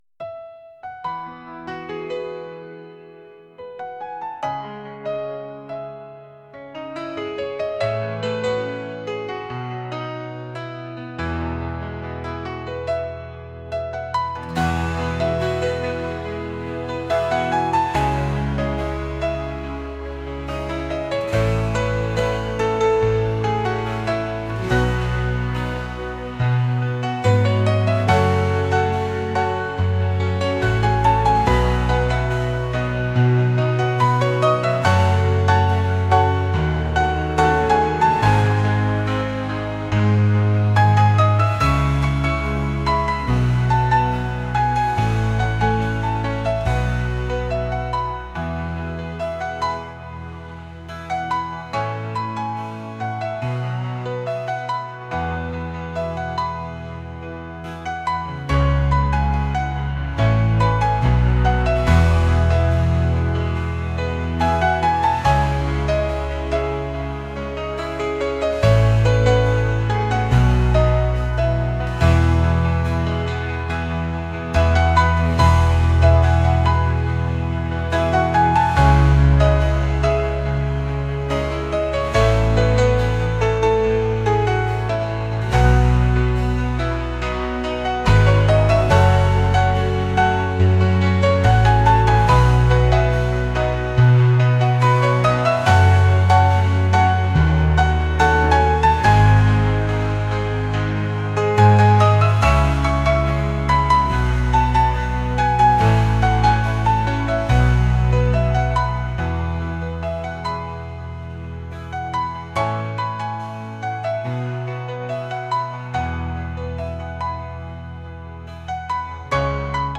pop | acoustic | folk